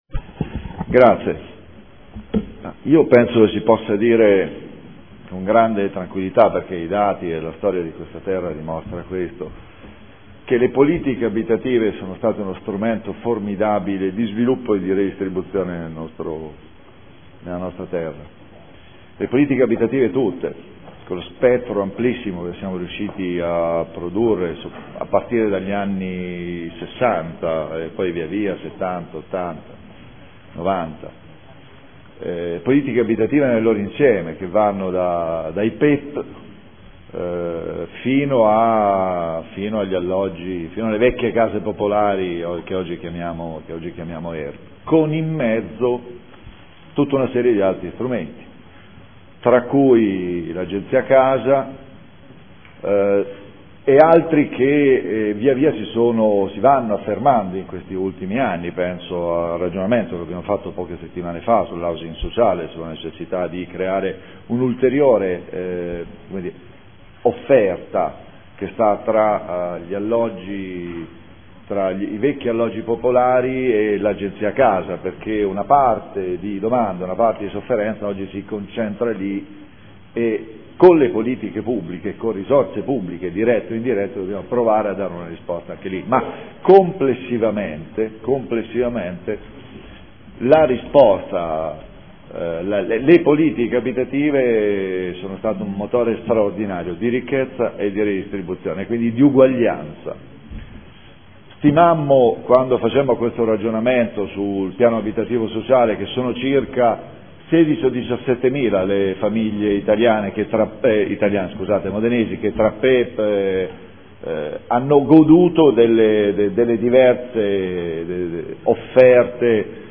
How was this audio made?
Seduta del 11/12/2014 Linee guida per l’appalto per la gestione di attività e progetti orientati all’educazione all’abitare, mediazione condominiale e di vicinato – Periodo dall’1.4.2015 al 30.9.2016 Audio Consiglio Comunale